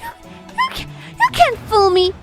Worms speechbanks
Coward.wav